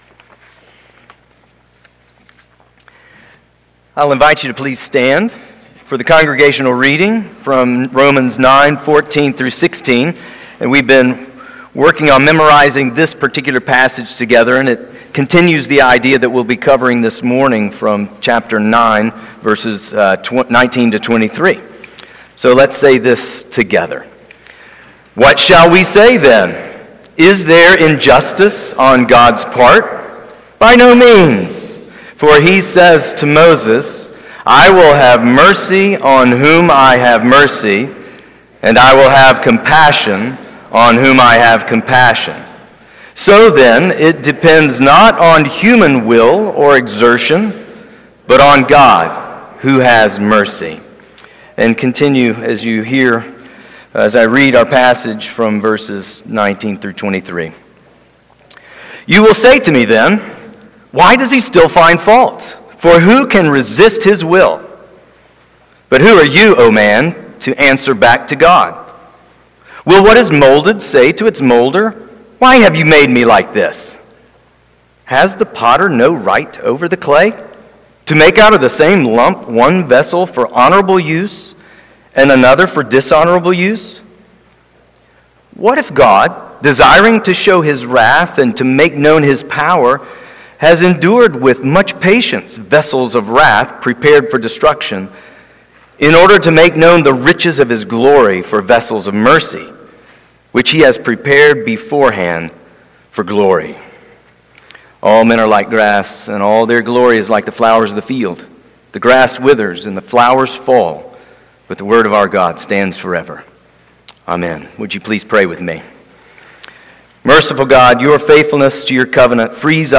Romans 9:19-23 Service Type: Sunday Morning Download Files Bulletin « The Just God?